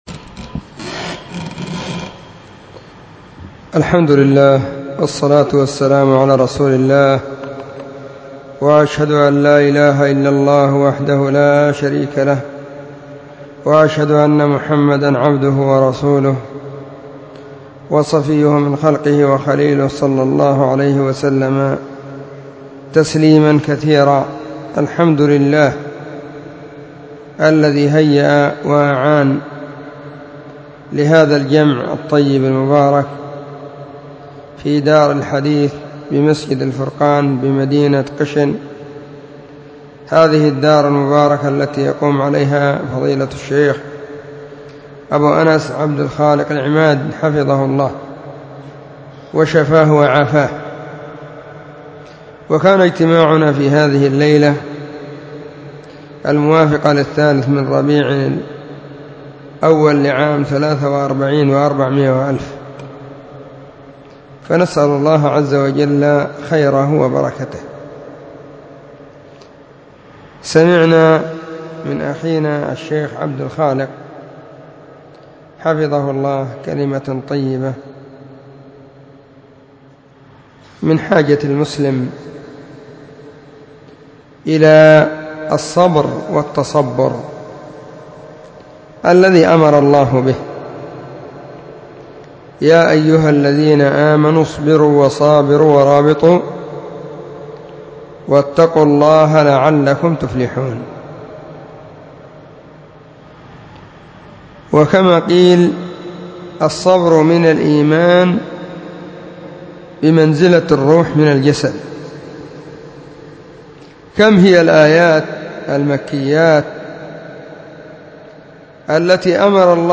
محاضرة بعنوان *الصبر والتصبر*
📢 مسجد الصحابة – بالغيضة – المهرة، اليمن حرسها الله،